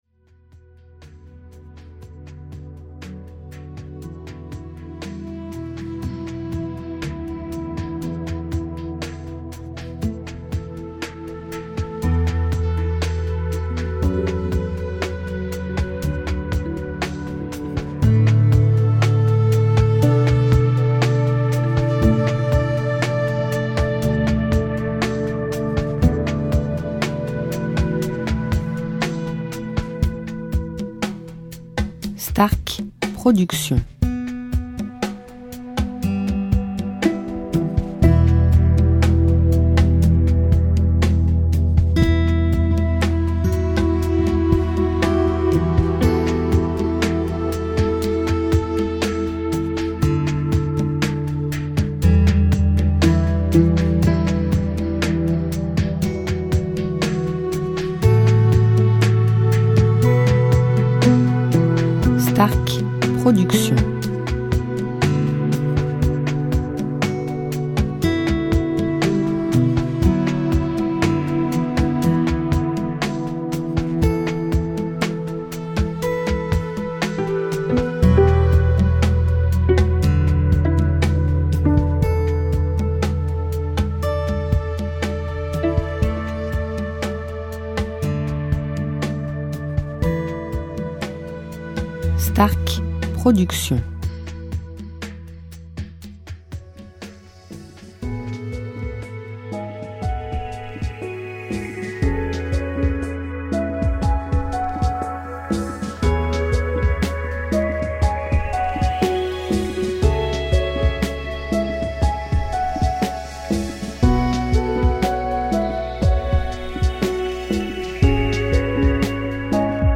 style Chillout Lounge durée 1 heure